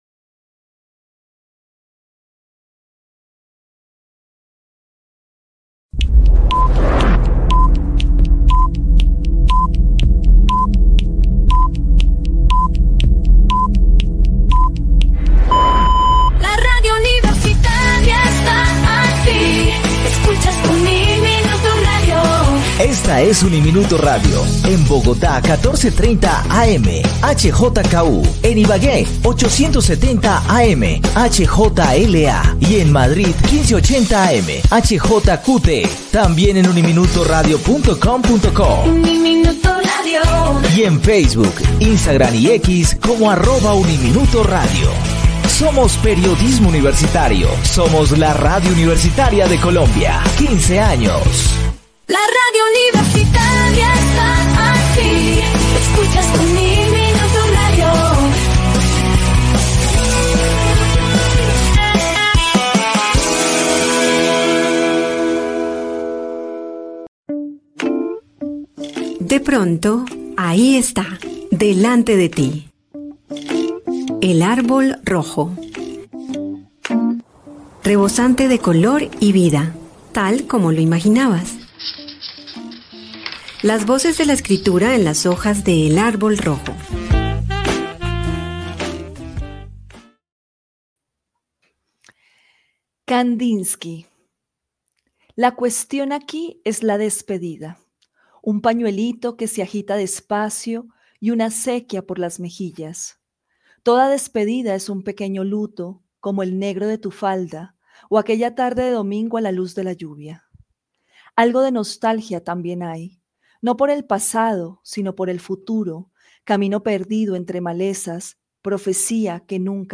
El Árbol Rojo: conversación